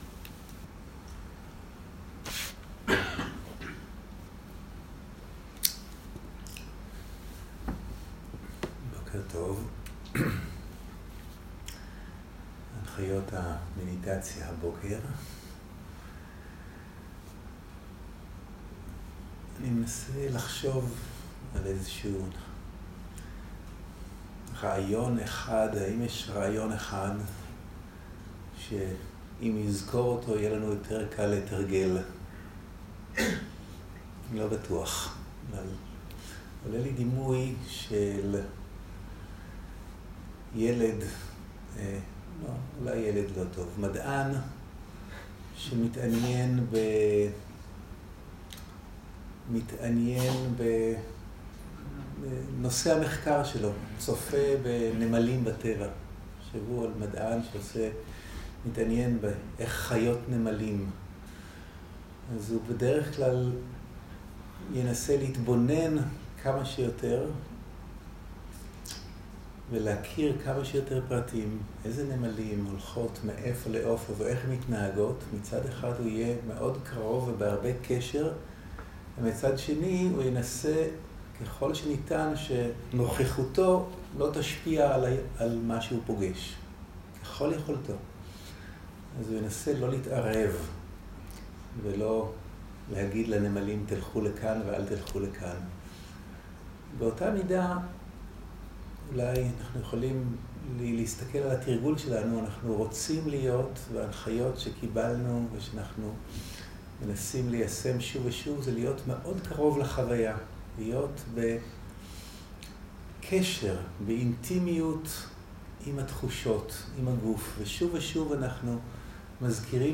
סוג ההקלטה: שיחת הנחיות למדיטציה
איכות ההקלטה: איכות גבוהה